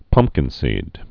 (pŭmpkĭn-sēd, pŭm-, pŭng-)